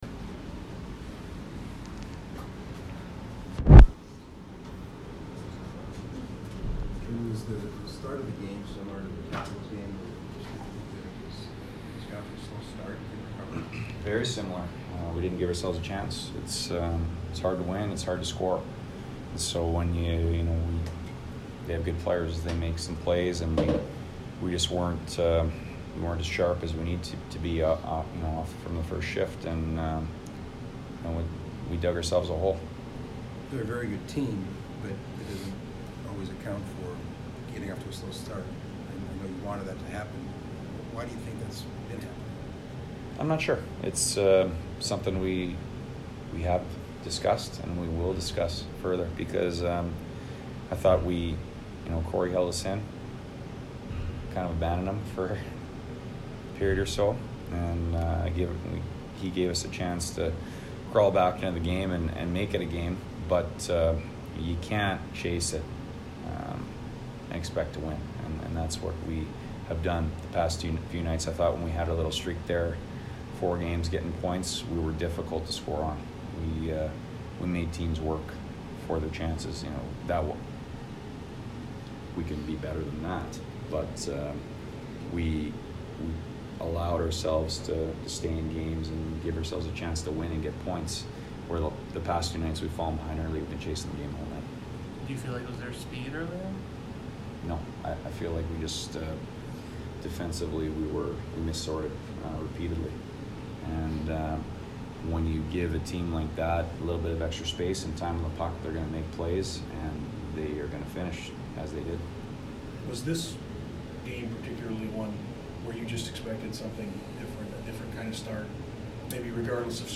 Jeremy Colliton post-game 11/23